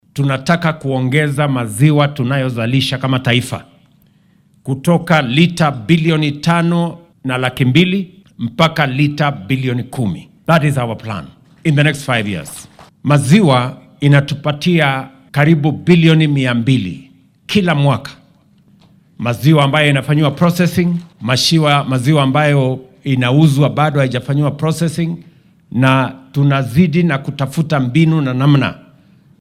Arrimahan ayuu ka hadlay xilli uu Kiganjo oo ka tirsan ismaamulka Nyeri uu ku daahfuray warshadda caanaha ee KCC oo la casriyeeyay.